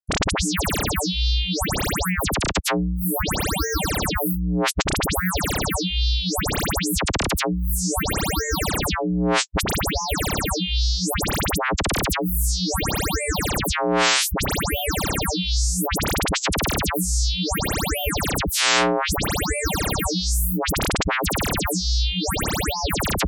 Additive Synthesis
When combShape = 1, you get a nice intermediate pausing / cadence effect because all phases are set to 0 momentarily, if combOffset is bigger then the scaled ratios.
The way i have set this up always gives me future dancehall vibes haha